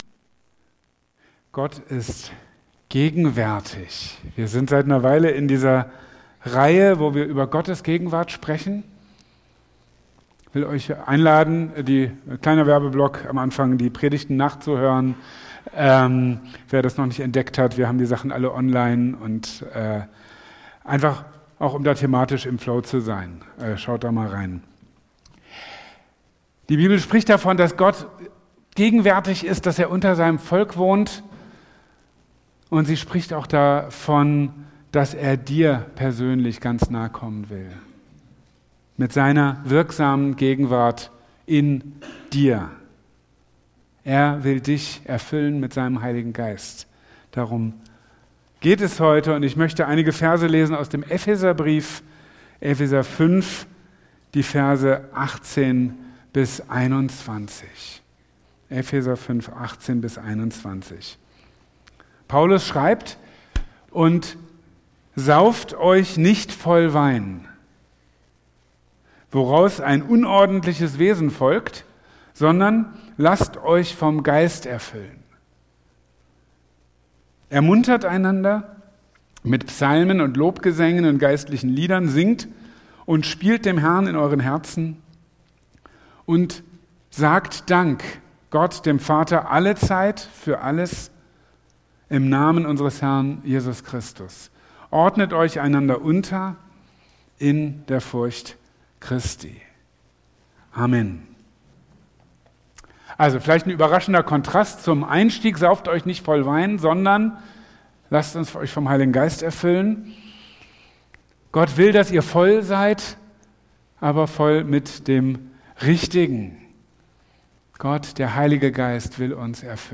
Predigten aus der Anskar-Kirche Marburg